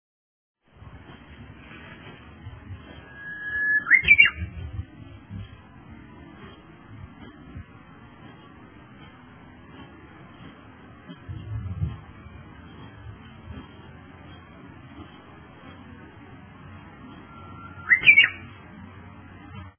とれたてのウグイス
新鮮なウグイスの鳴き声をお届けします。 先ほど庭先で録音したばかり。